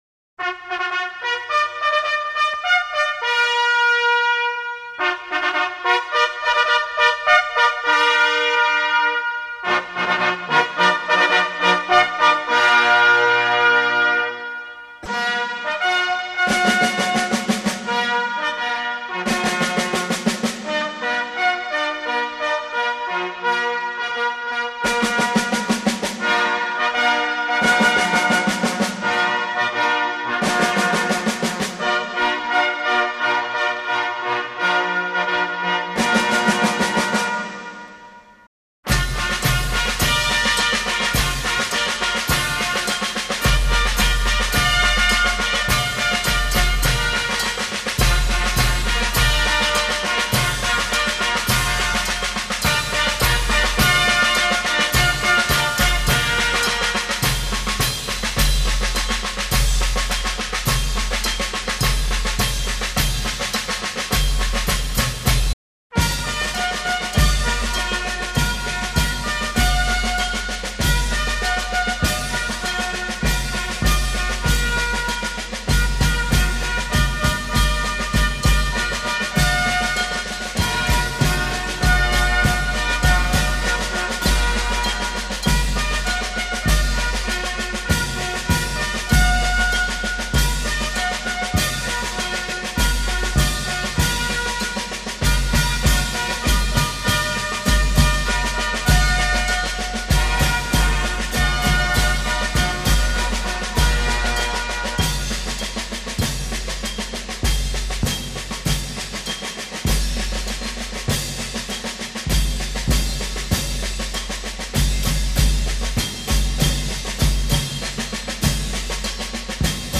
集合号+升旗曲+出旗曲+退旗曲+行进曲